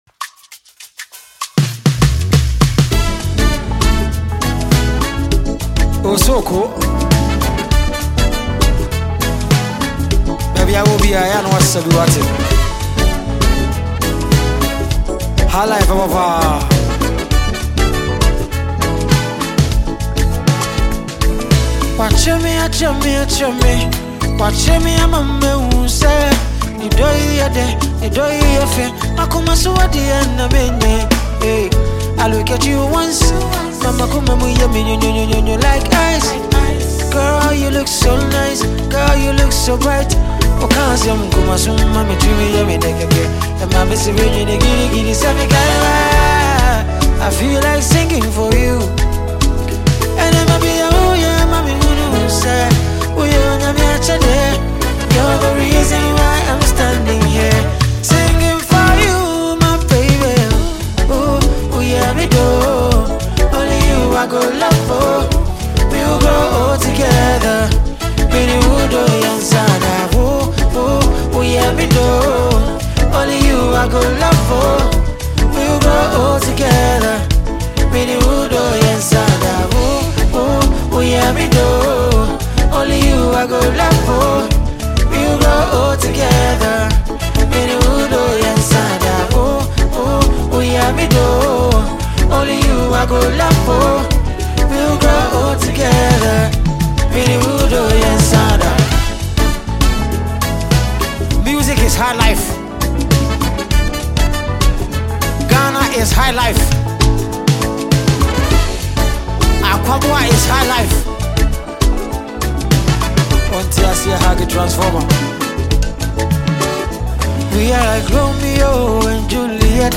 this is a live performance